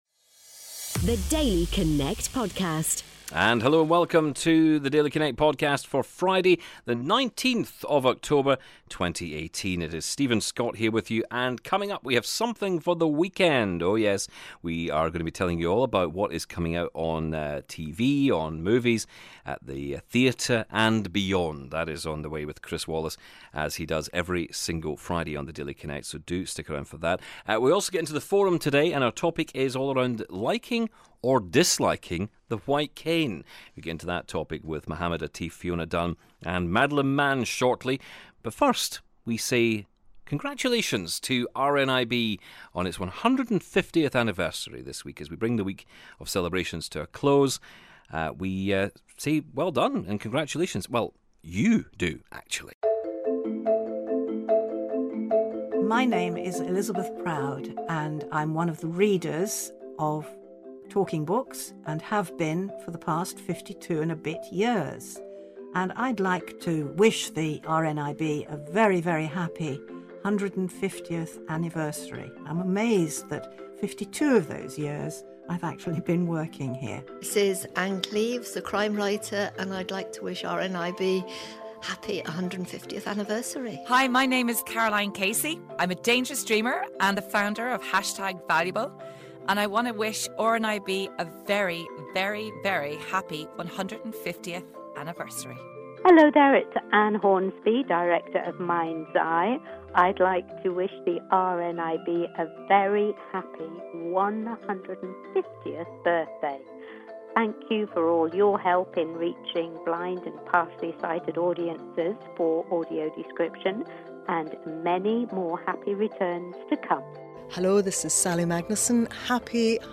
and we also hear some wonderful congratulatory messages from you, for RNIB's 150th Birthday.